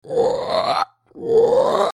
Download Toad sound effect for free.
Toad